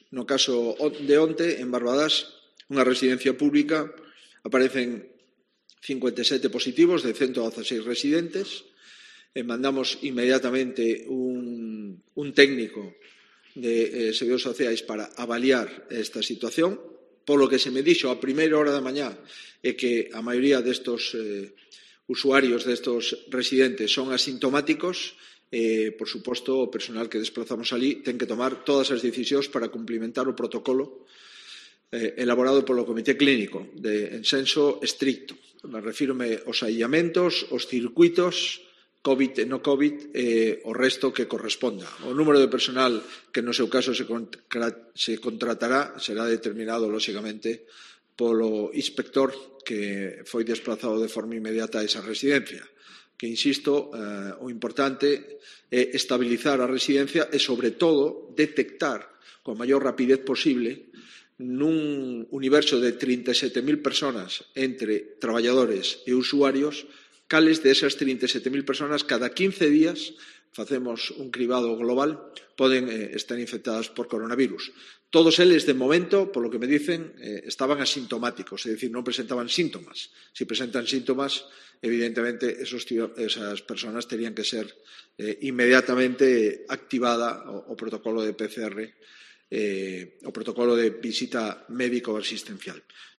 Declaraciones de Alberto Núñez Feijóo sobre la residencia de Barbadás